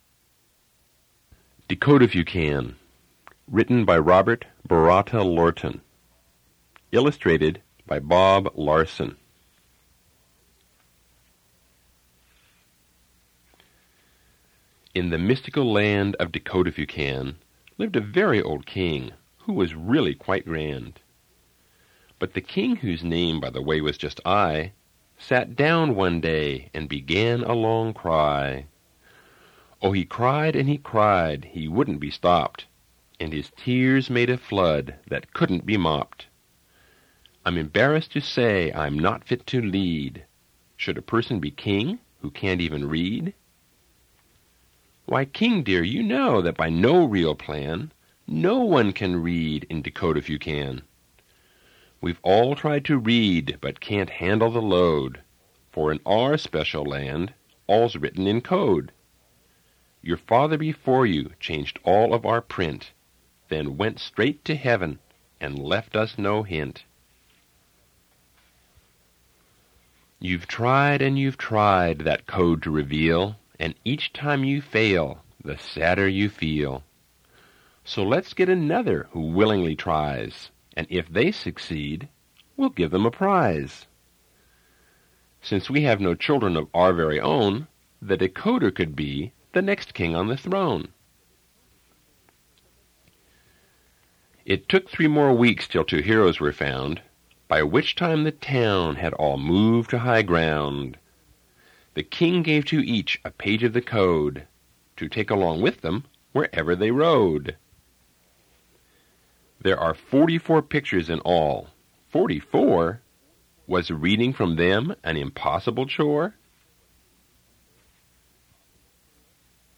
Reading of Dekodiphukan